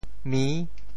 “媚”字用潮州话怎么说？
媚 部首拼音 部首 女 总笔划 12 部外笔划 9 普通话 mèi 潮州发音 潮州 mi6 文 中文解释 媚 <动> (形声。
mi6.mp3